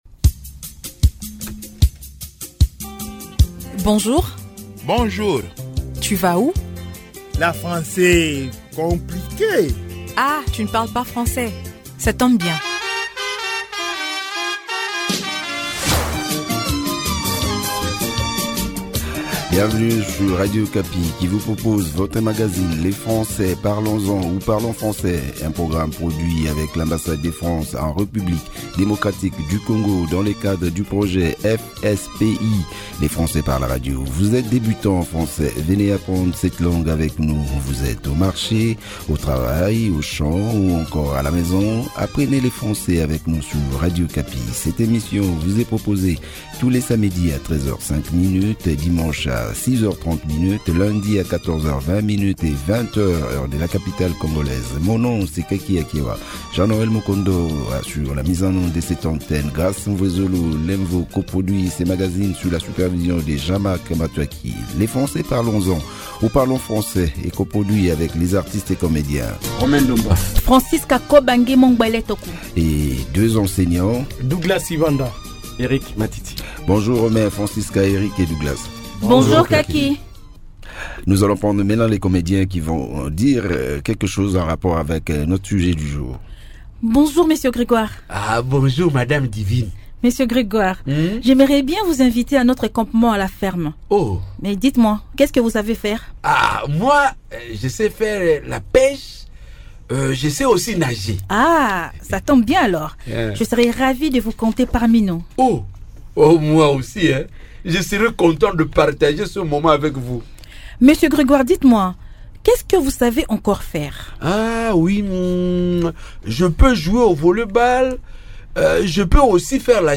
L’équipe composée de deux enseignants, deux comédiens et un animateur vous laisse le temps de suivre.